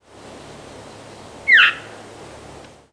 Veery diurnal
Glossary Species Home Veery diurnal flight calls Fig.2. New York May 29, 1989 (WRE).
Perched bird with Scarlet Tanager singing in the background.